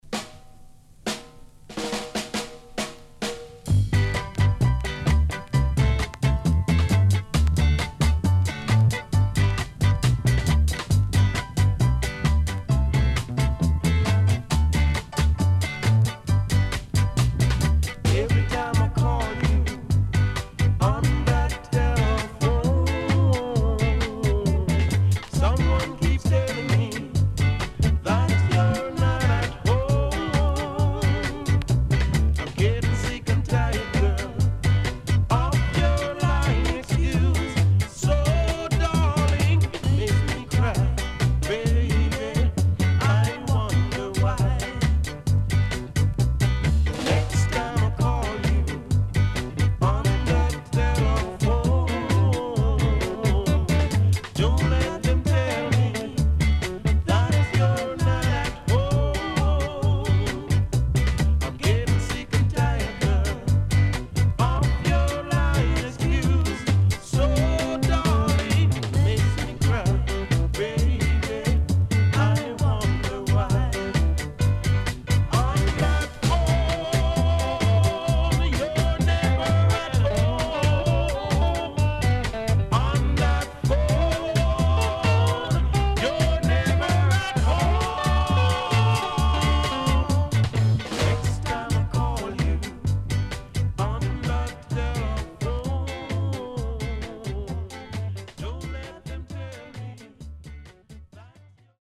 HOME > REGGAE / ROOTS  >  EARLY REGGAE
W-Side Good Early Reggae
SIDE A:少しチリノイズ、中盤に少しプチノイズ入ります。